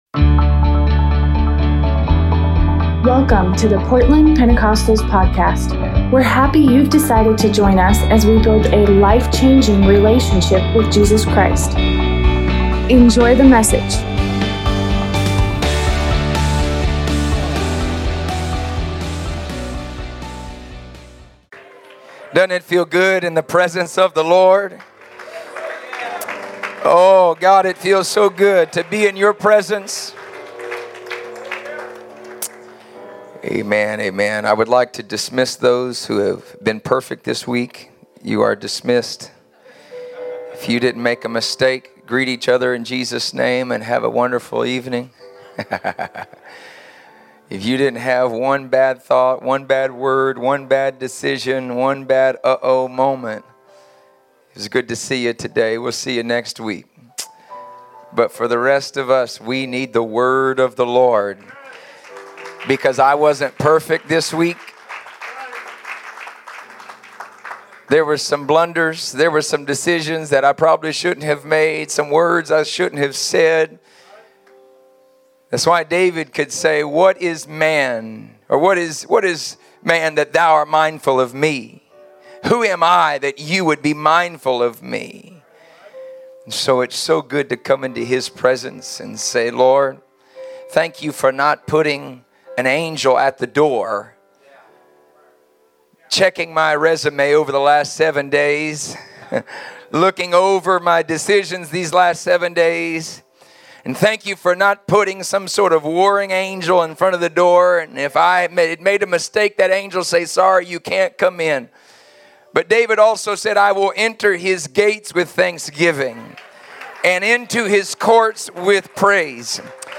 Sunday revival service